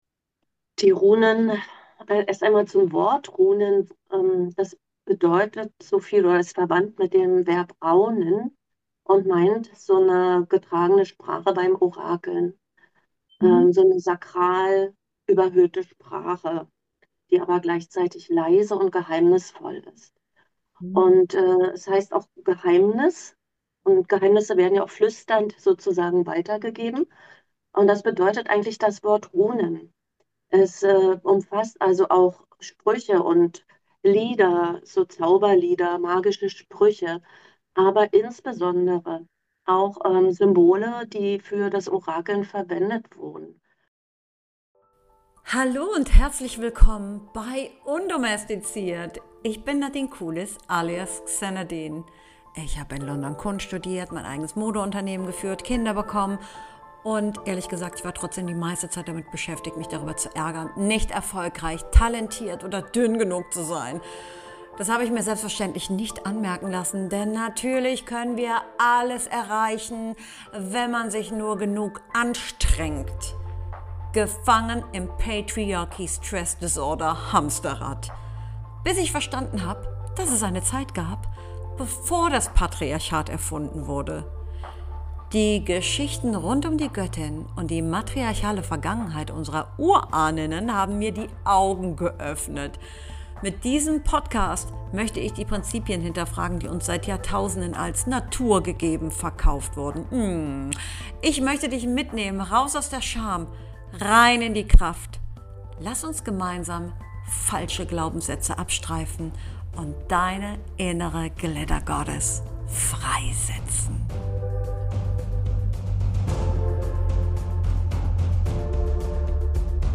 #58 Runen – Uralte Zeichen, zeitlose Kraft | Im Gespräch